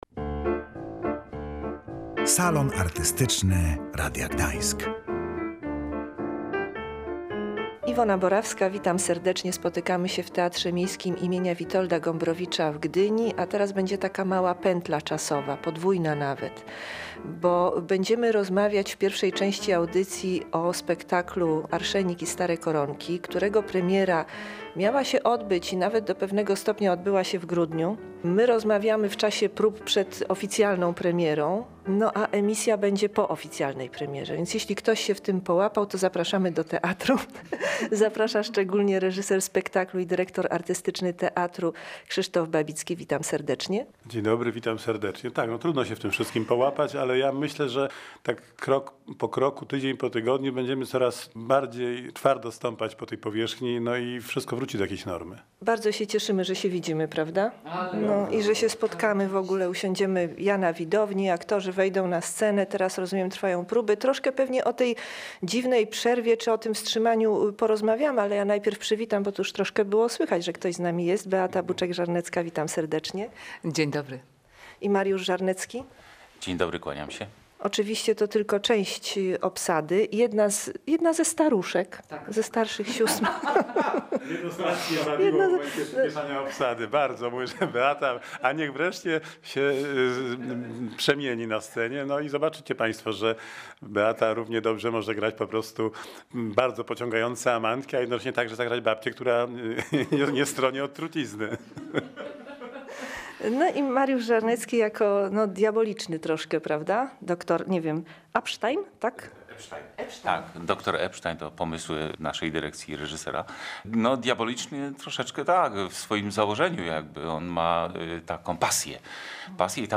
W audycji byliśmy z wizytą w Teatrze Miejskim im. W. Gombrowicza w Gdyni.